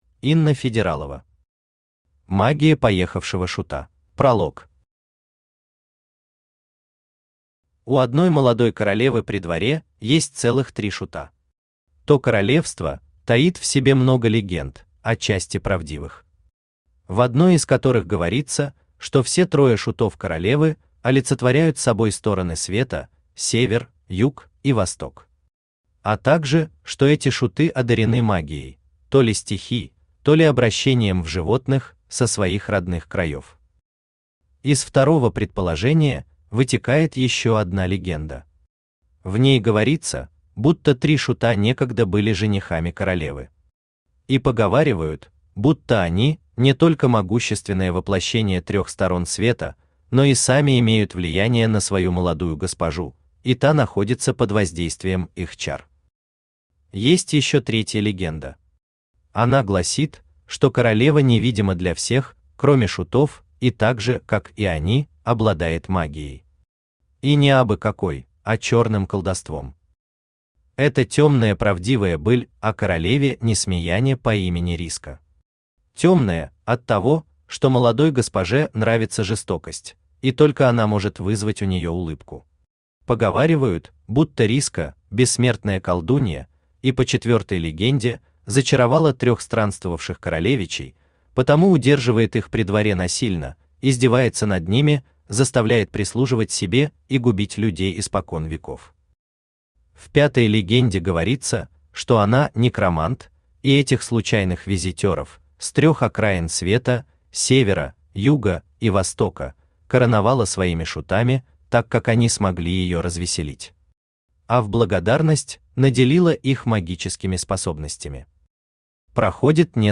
Аудиокнига Магия поехавшего шута | Библиотека аудиокниг
Aудиокнига Магия поехавшего шута Автор Инна Федералова Читает аудиокнигу Авточтец ЛитРес.